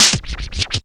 KICK N SNR.wav